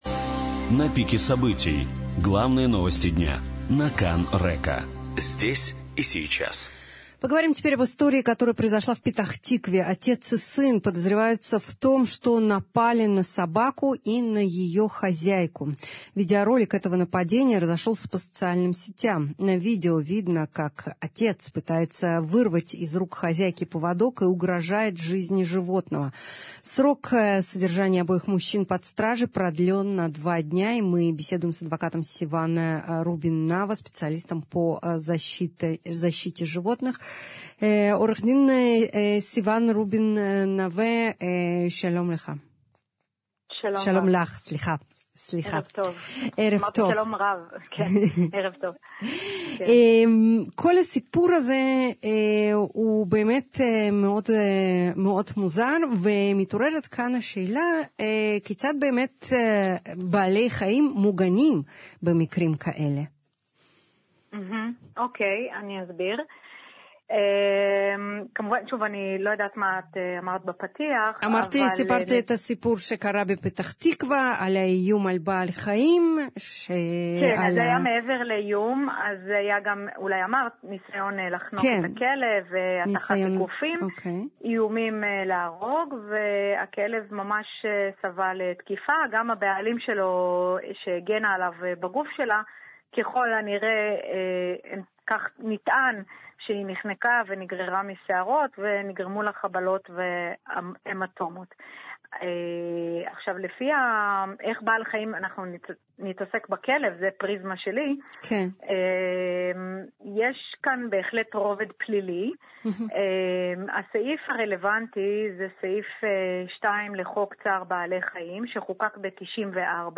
ראיון אודות האירוע בפתח תקווה
מתוך תוכנית רדיו מיום 14/04/2025 (חלקו ברוסית)